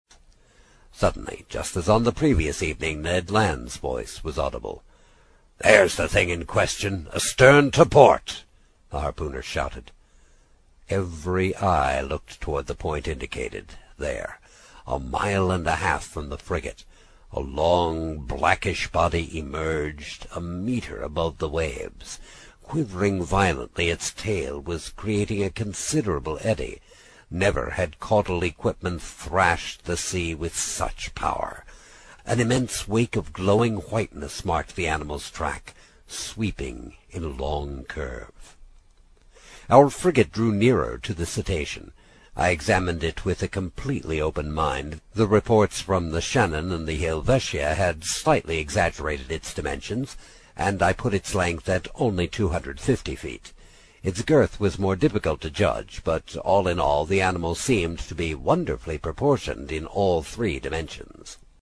英语听书《海底两万里》第67期 第6章 开足马力(9) 听力文件下载—在线英语听力室
在线英语听力室英语听书《海底两万里》第67期 第6章 开足马力(9)的听力文件下载,《海底两万里》中英双语有声读物附MP3下载